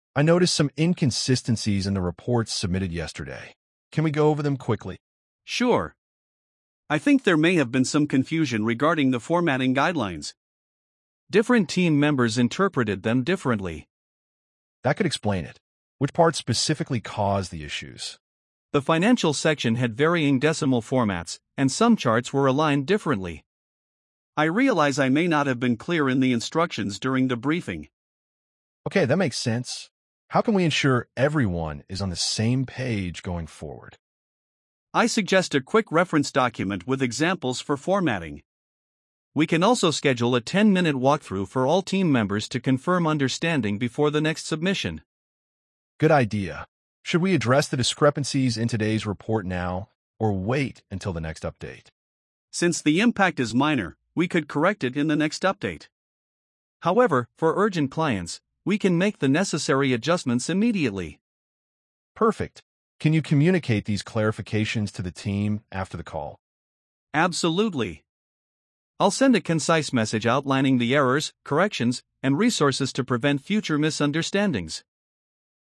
🤝 A team lead addresses inconsistencies in submitted reports.